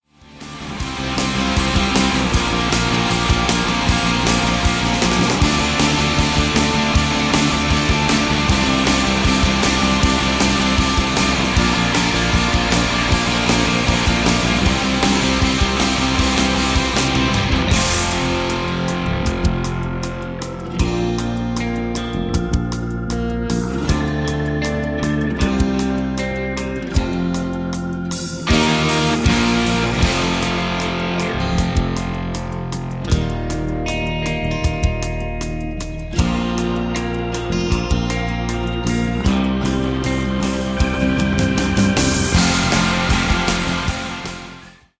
• Sachgebiet: Musicals